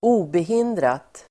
Ladda ner uttalet
obehindrat adverb, unhindered Uttal: [²'o:behin:drat]